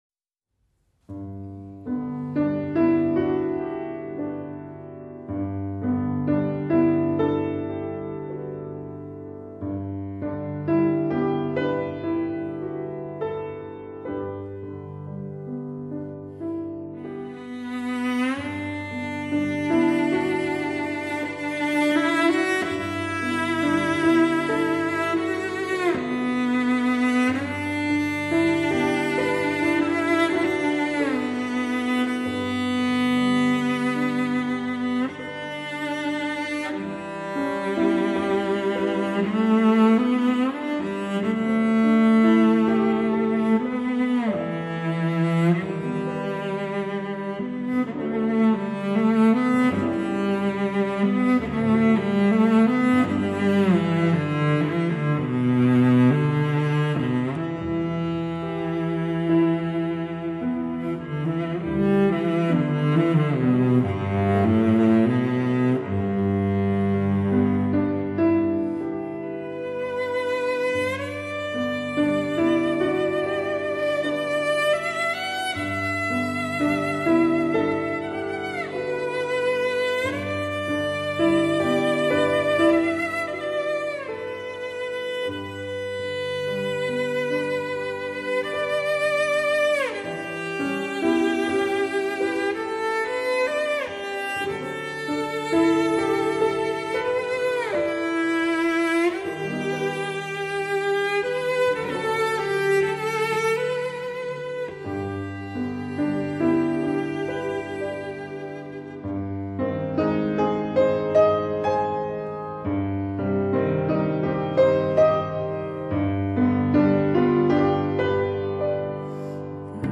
大提琴
钢琴
录音棚：中国人民解放军军乐团录音棚
两缕琴声 几世怀想 交缠 沉吟 如影随形......